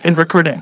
synthetic-wakewords
ovos-tts-plugin-deepponies_Apple Bloom_en.wav